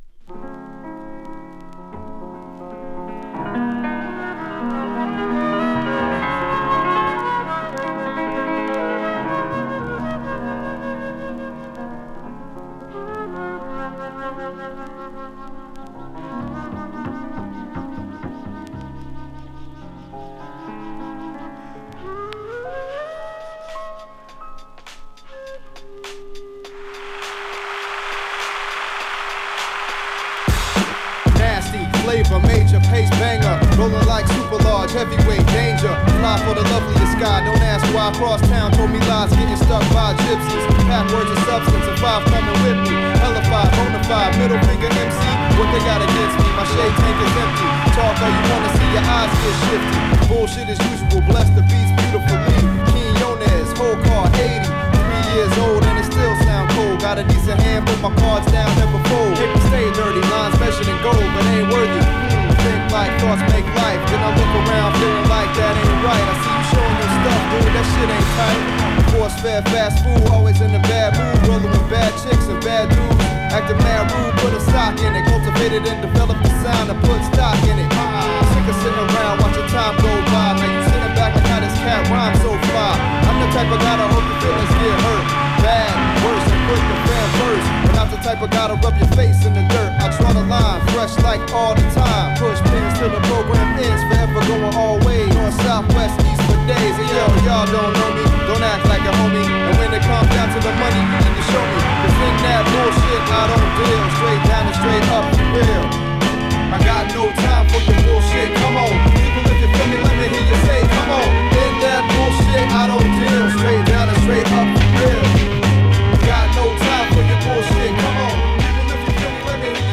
フルートの鳴りが印象的なスピリチュアルなネタとファットなビートが絡み合い軽快なフロウが展開。